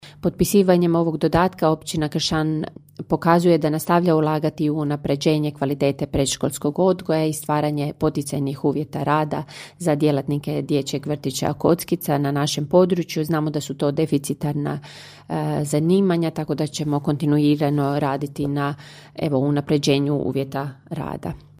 ton – Ana Vuksan), ustvrdila je općinska načelnica Kršana Ana Vuksan.